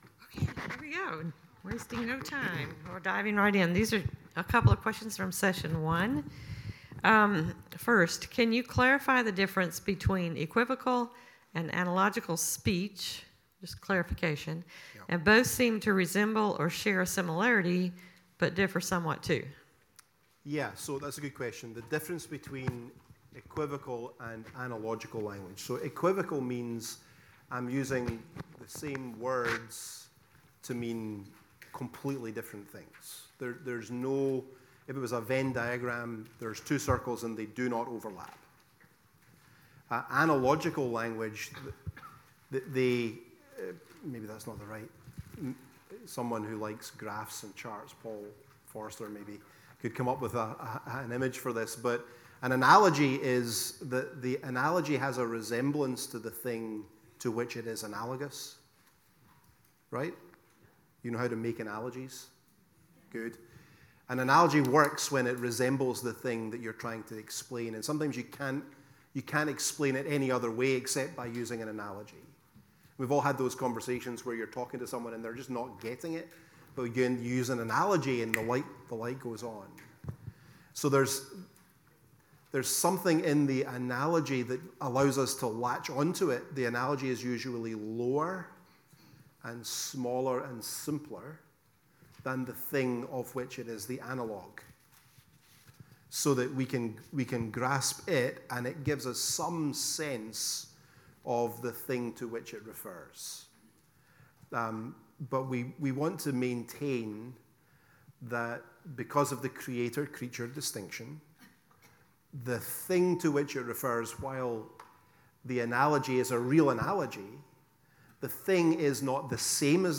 The-Doctrine-of-God-Session-4-Question-and-Answer.mp3